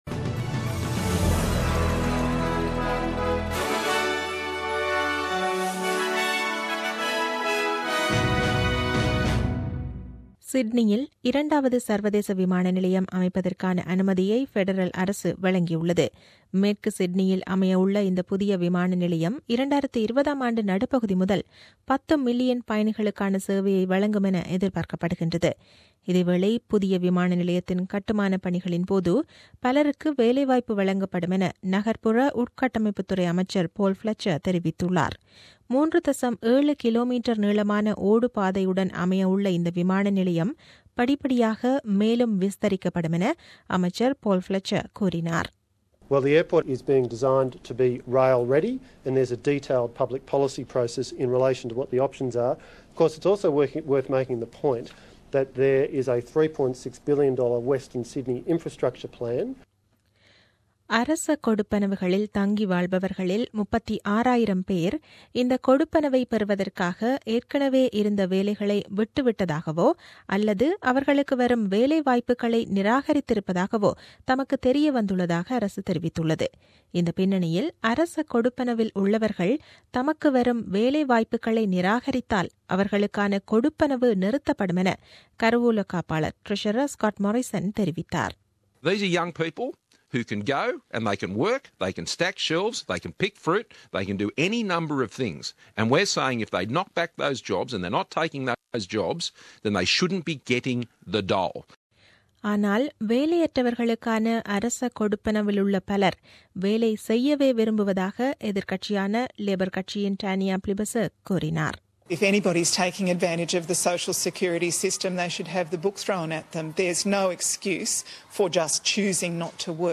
The news bulletin aired on 12 Dec 2016 at 8pm.